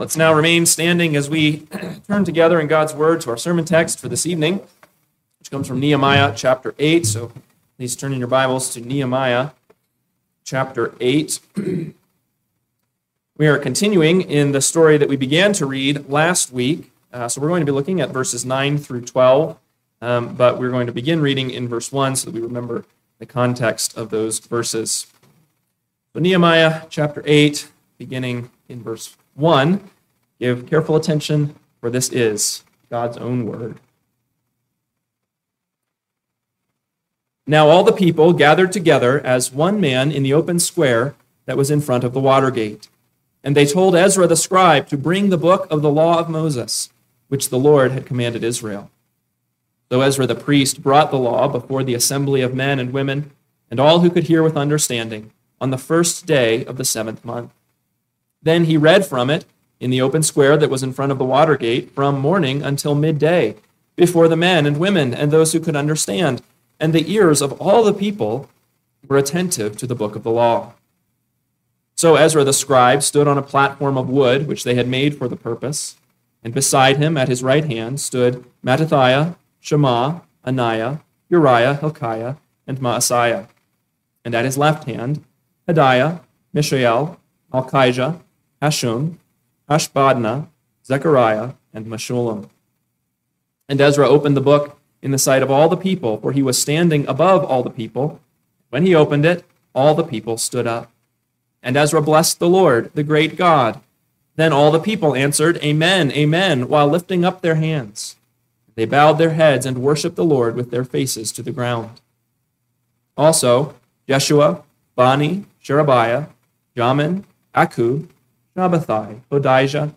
PM Sermon – 10/5/2025 – Nehemiah 8:9-12 – Northwoods Sermons